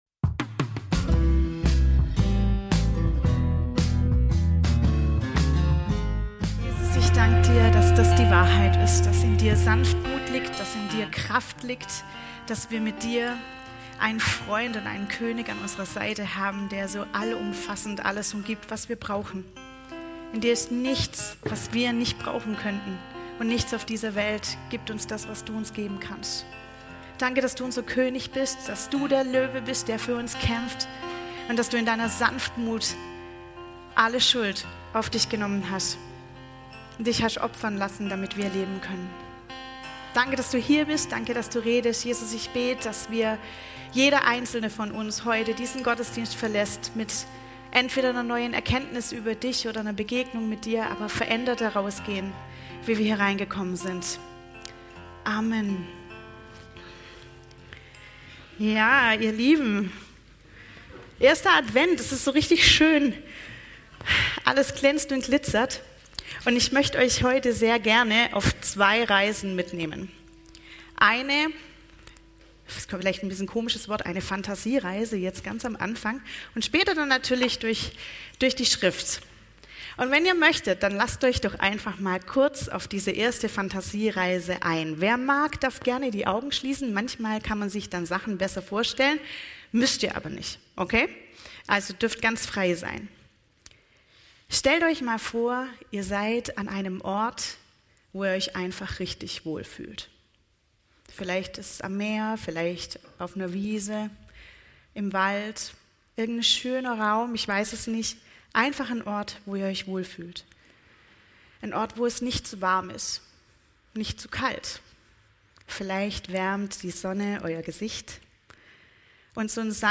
alle Predigten